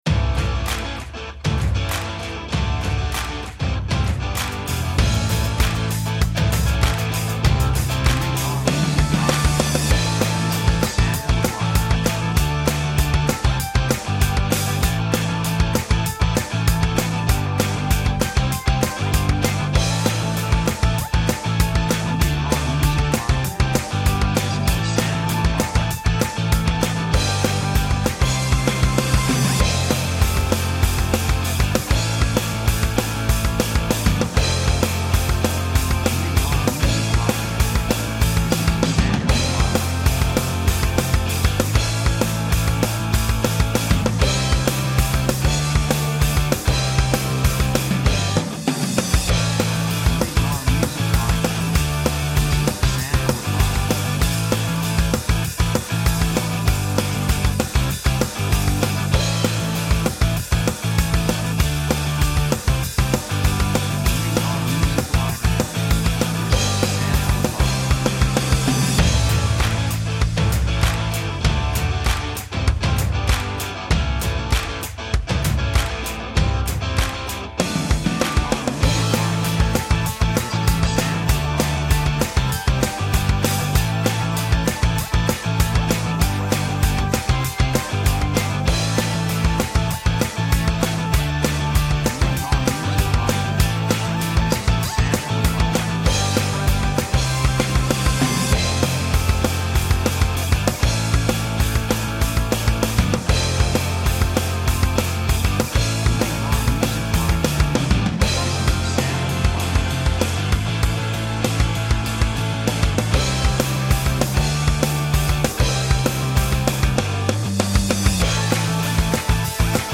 雰囲気エネルギッシュ, 壮大, 幸せ, 高揚感, 喜び
曲調ポジティブ
楽器エレキギター, 手拍子
サブジャンルポップロック, インディーロック
テンポとても速い